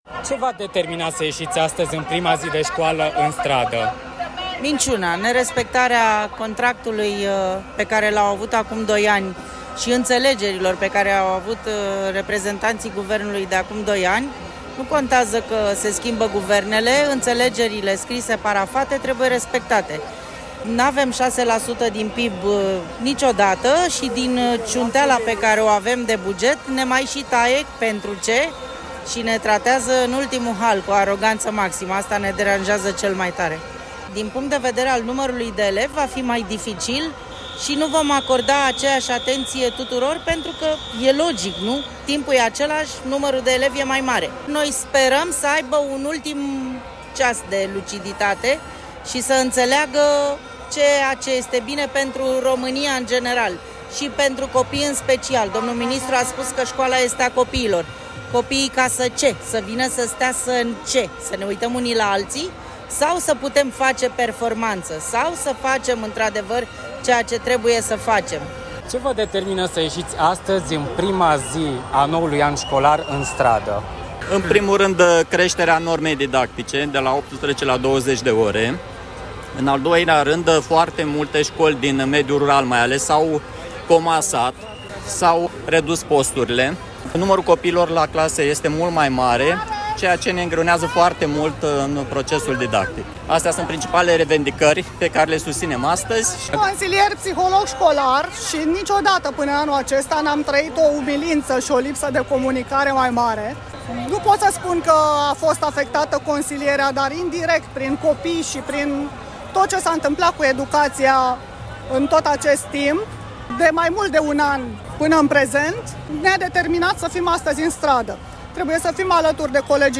UPDATE: Ce spun profesorii, de la protestele din Piata Victoriei: